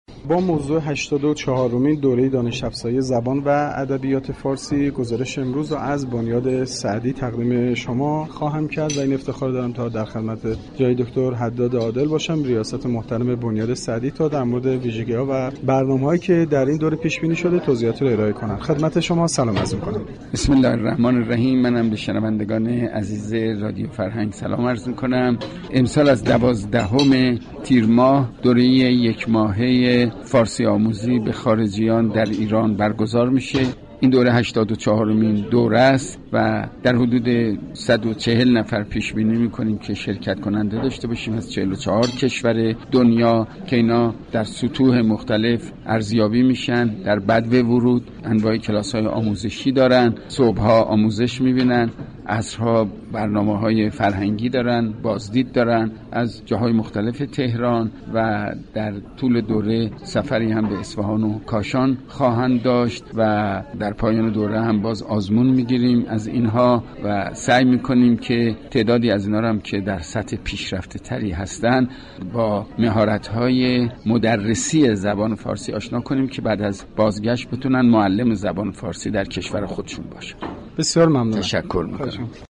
دكتر غلامعلی حداد عادل ریاست محترم بنیاد سعدی در گفتگوی اختصاصی با گزارشگر رادیو فرهنگ درباره برنامه هایی كه در این دوره پیش بینی شده گفت : امسال از دوازدهم تیرماه هشتاد و چهارمین دوره ی یك ماهه ی فارسی آموزی به خارجیان در آیان آغاز شده و از 44 كشور دنیا در حدود 140 شركت كننده در این دوره حضور دارند .